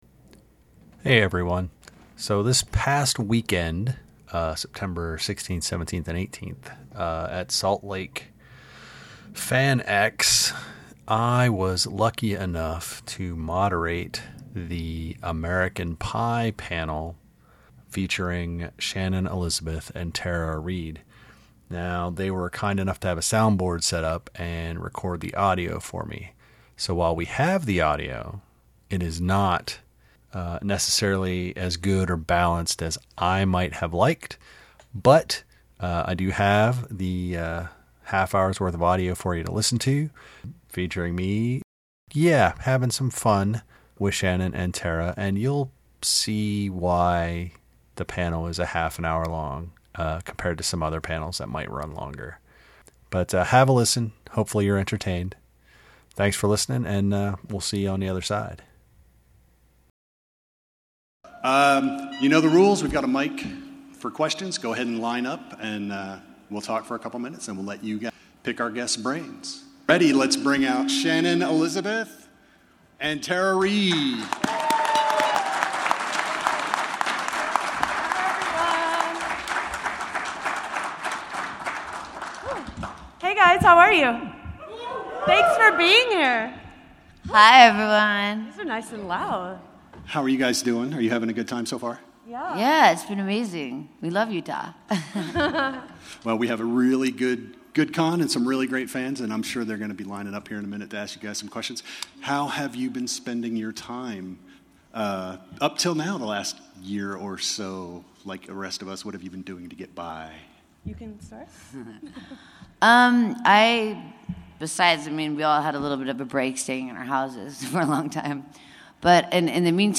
FanX 2021 - American Pie panel —
If you have, then you already know that I got to moderate the American Pie panel, as both Shannon Elizabeth and Tara Reid were guests.